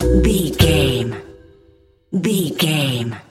Aeolian/Minor
strings
bass guitar
acoustic guitar
flute
percussion
silly
circus
goofy
comical
cheerful
perky
Light hearted
quirky